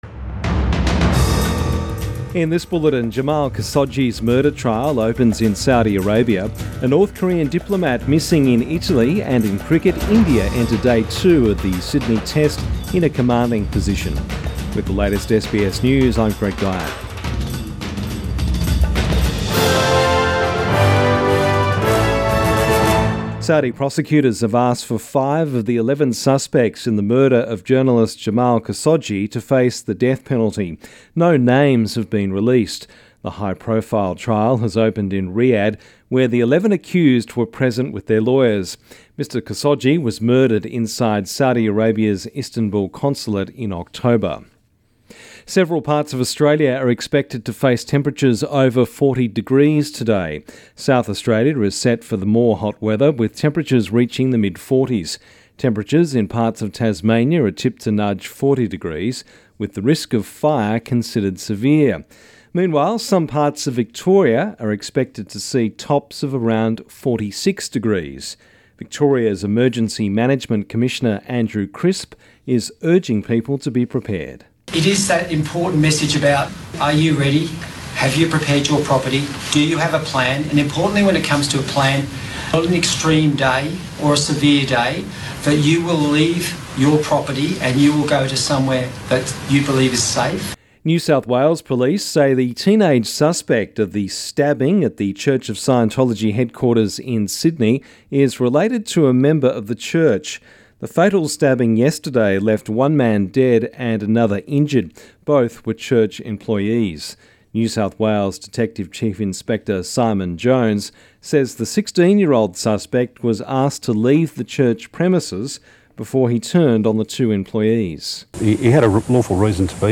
AM bulletin 4 January